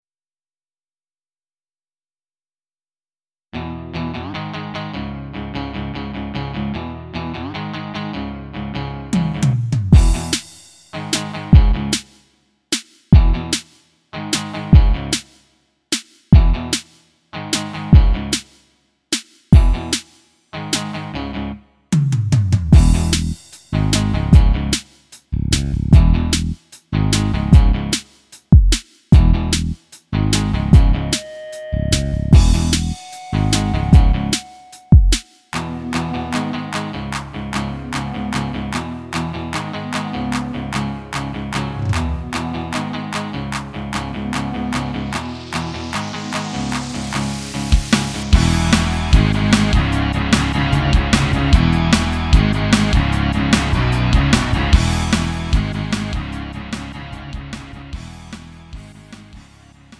음정 -1키 2:50
장르 가요 구분